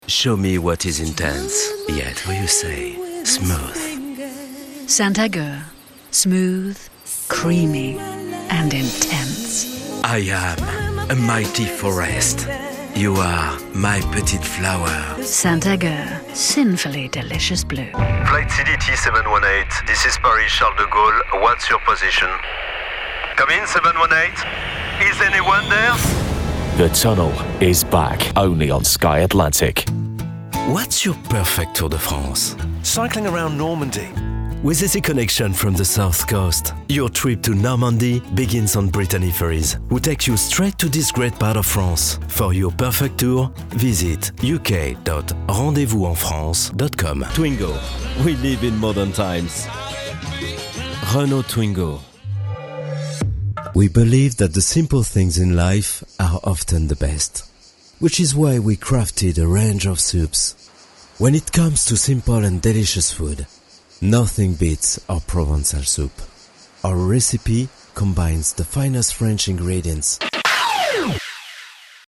Microphone: Neumann TLM103